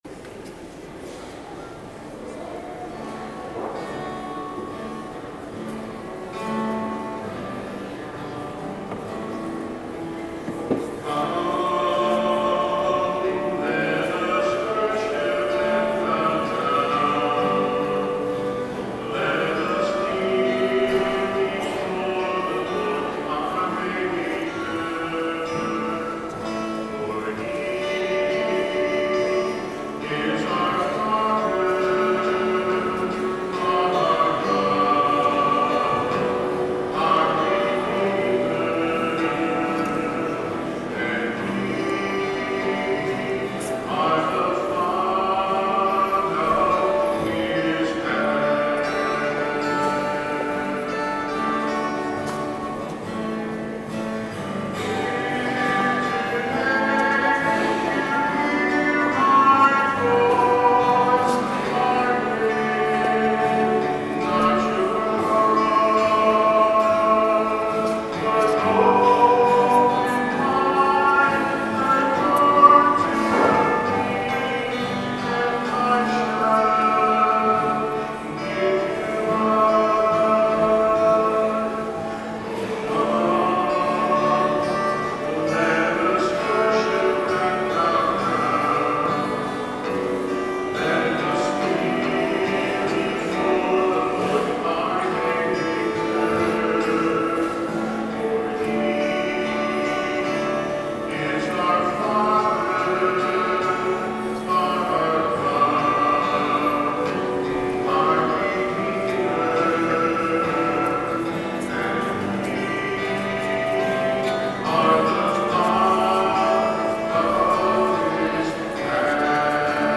10/06/13 10:30 Mass Recording of Music
Using the same technique as last Sunday (keeping the music in a single, continuous recording without adjusting the volume for individual songs), note the volumes of mics and instruments. Also note that based on feedback from Msgr., we lowered the overall volume since he said we were "too loud in general". ========================================== Music from the 10:30 Mass on the 27th Sunday in Ordinary Time, October 6, 2013: Note that all spoken parts of the Mass have been removed from this sequence, but ALL hymns and Mass parts are included. 100613 All Music.mp3